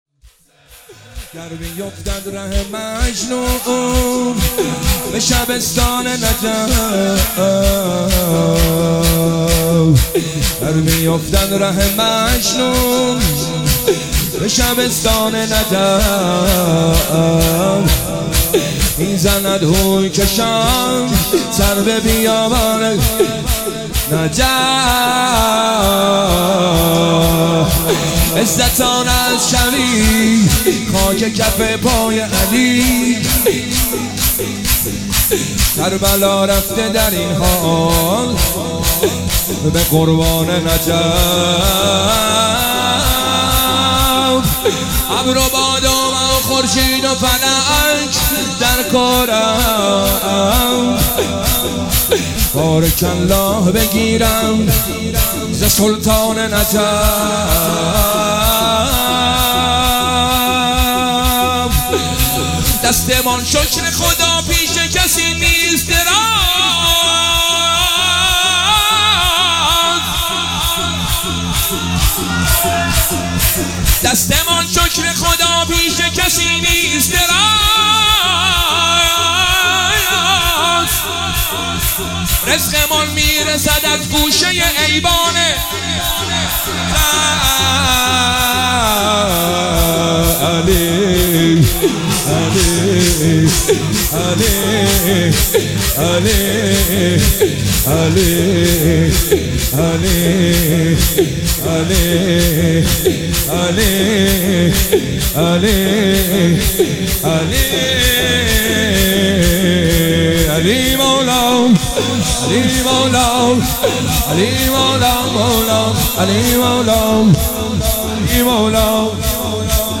مداحی شور شب 21 ماه رمضان شب قدر 1404